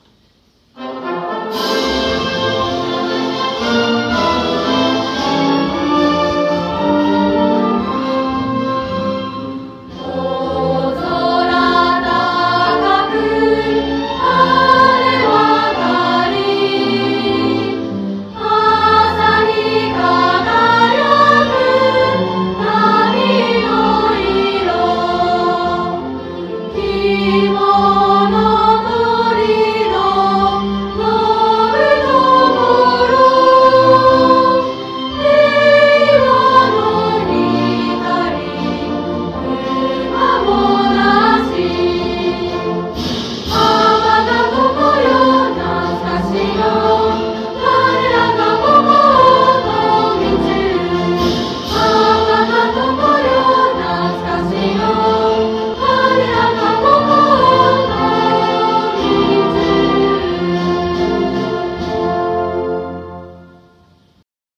校歌 - 延岡市立東海中学校
東海中学校校歌1番（オーケストラ、斉唱）.wav PDFファイルをご覧になるには、Acrobat Readerが必要です。